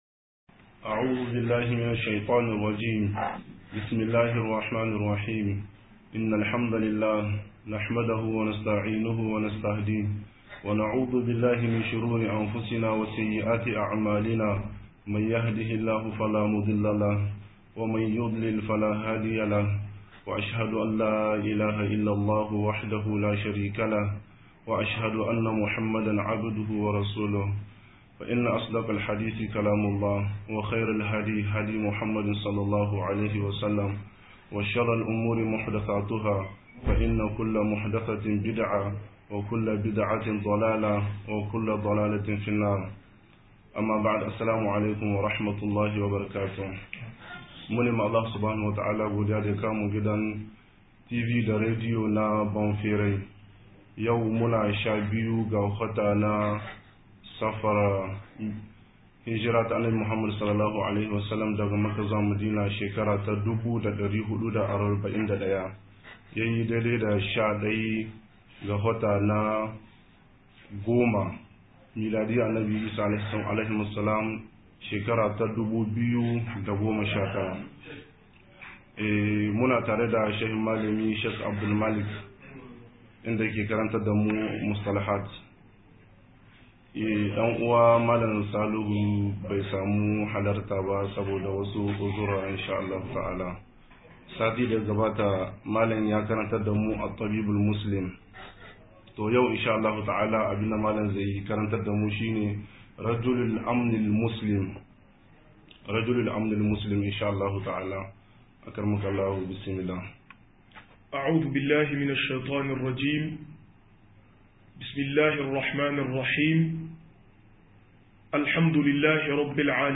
04-Army - MUHADARA